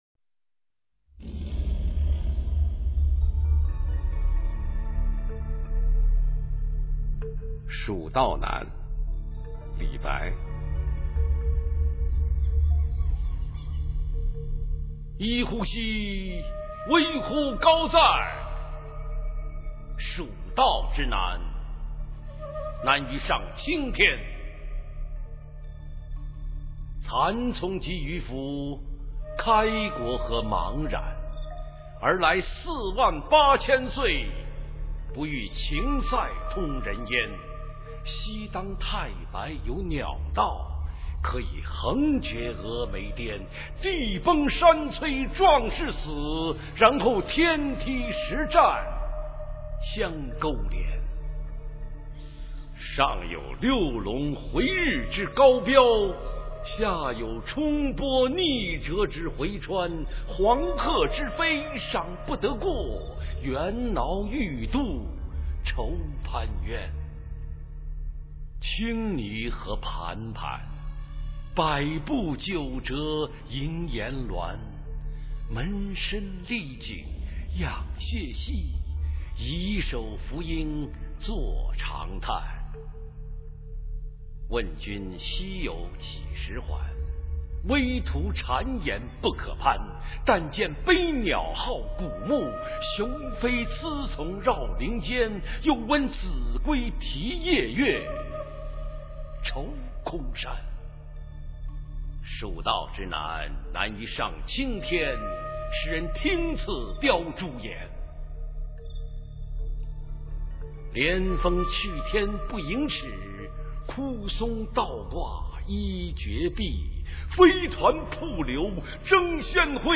《蜀道难》原文和译文（含鉴赏、朗读）　/ 李白